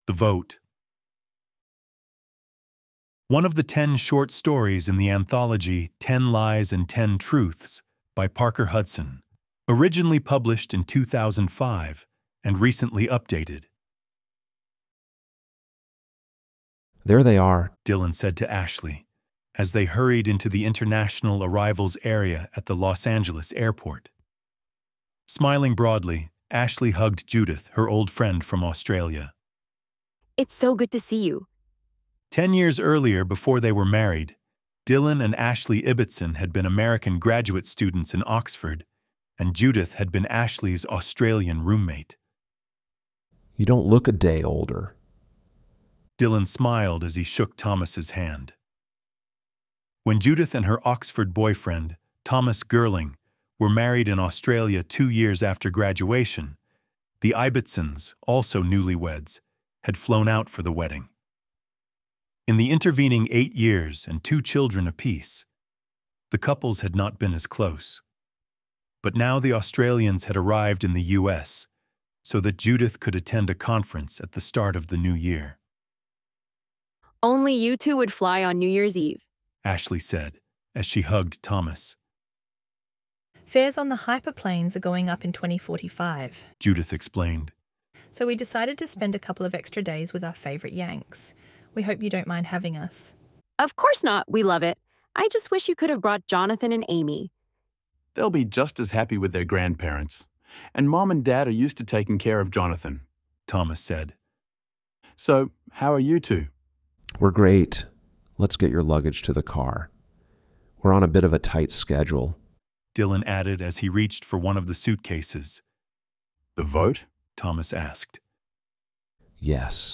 ElevenLabs_The_Vote_Eleven_Labs_Test_24_Aug_25.docx.mp3